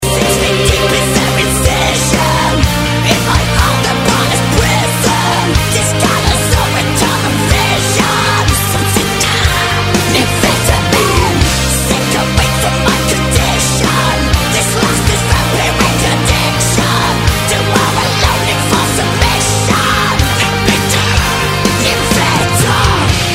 Назад в Нарезки мр3(rock